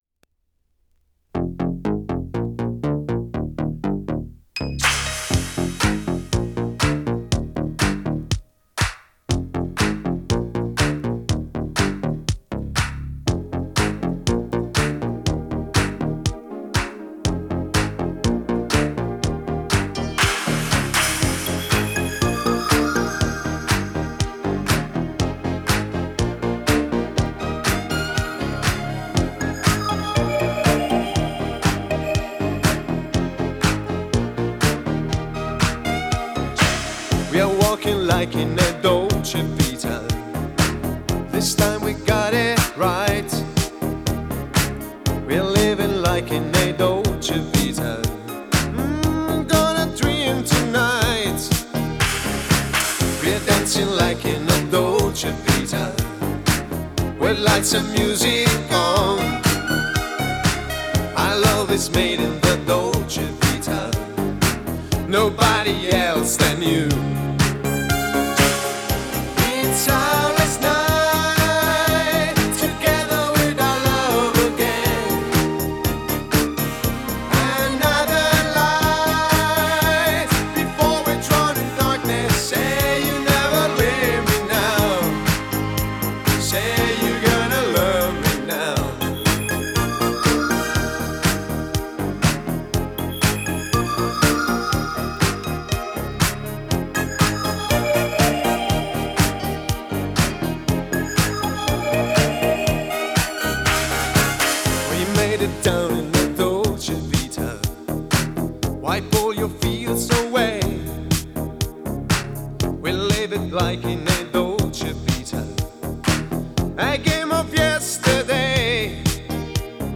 Pop, Italo-Disco
立体声
(Vocal)